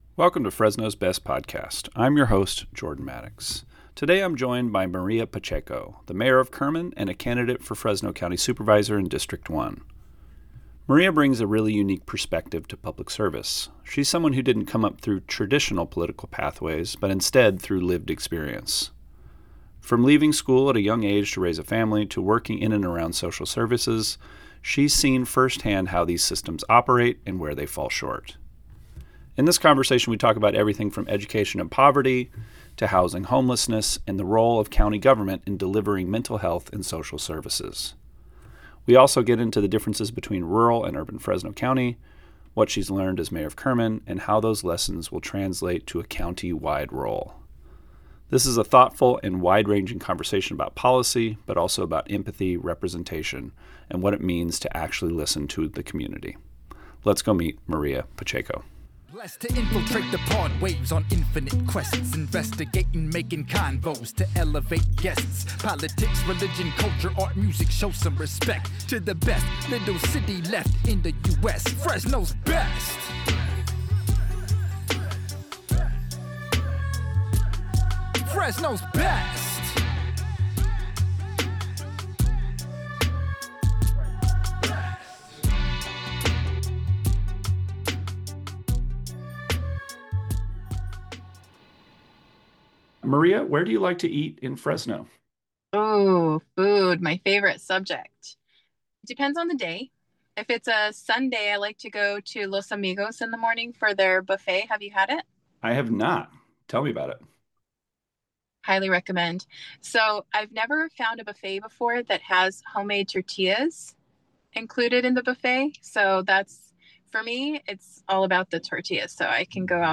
sits down with Kerman Mayor Maria Pacheco, who is now running for Fresno County Supervisor, District 1.